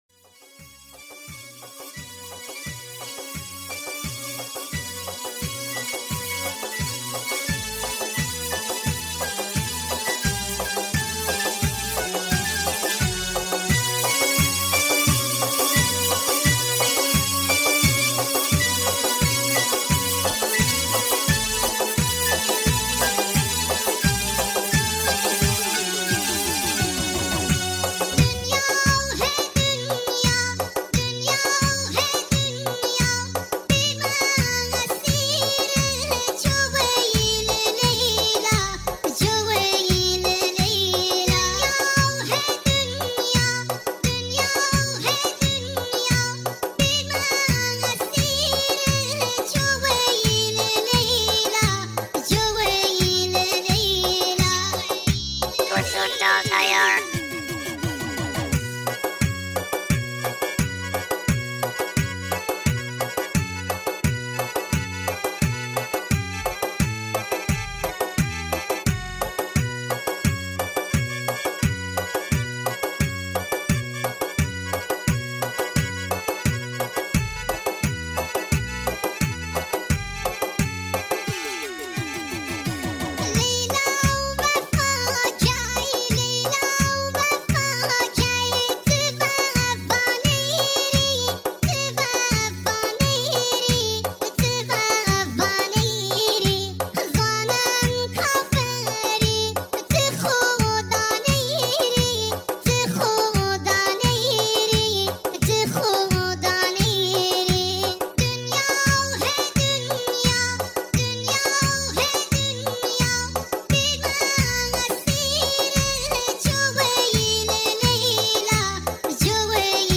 غمگین کردی کرمانشاهی لکی
با صدای نازک شده بچه بچگانه عروسکی